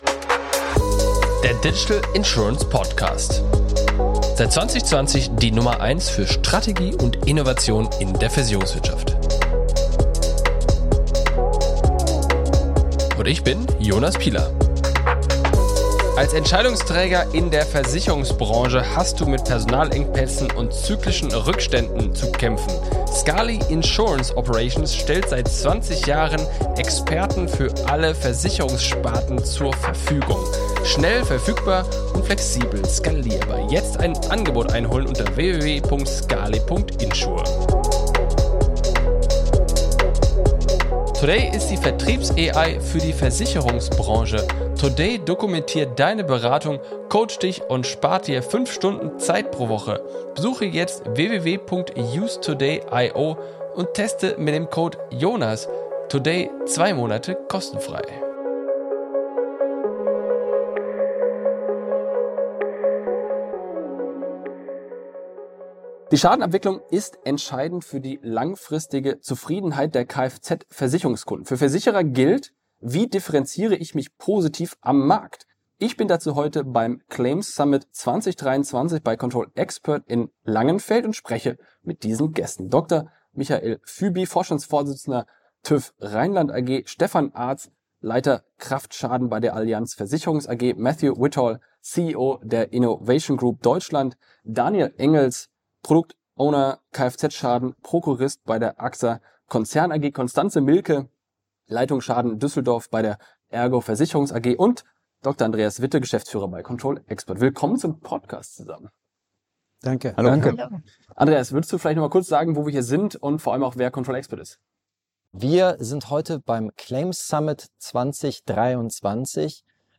Ich war in diesem Jahr auf dem Claim Summit bei ControlExpert zu Gast. Hier konnte ich sechs namhafte Gäste aus dem Bereich Schadenabwicklung interviewen. Die Themenschwerpunkte lagen auf den Differenzierungsmöglichkeiten und der Wichtigkeit der Schadenabwicklung für die Kundenzufriedenheit.